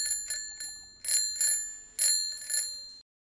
timbre de bicicleta
bell bell-tone bicycle chime ding dong ping ring sound effect free sound royalty free Sound Effects